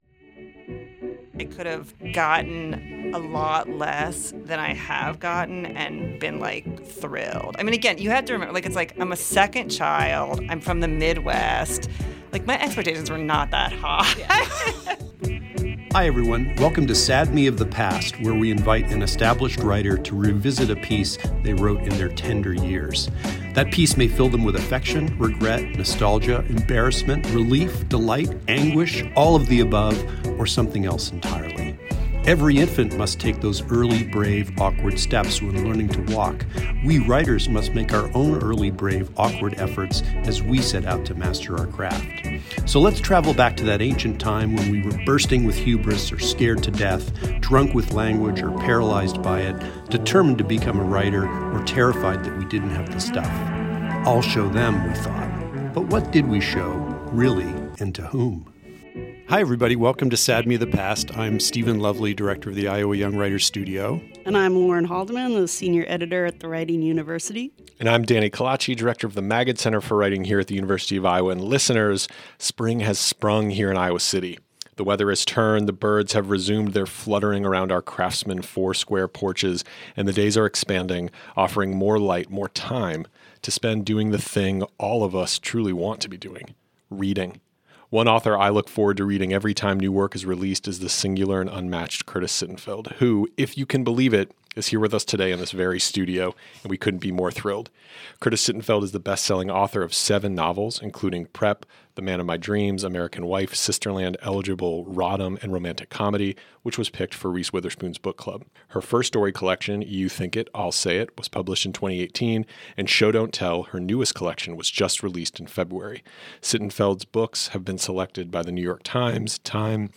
On this episode, we have bestselling author Curtis Sittenfeld in the studio! Curtis talks with us about the perils of boarding school, getting good juju from a used car, how her family really feels about her writing, and her new short story collection Show Don’t Tell.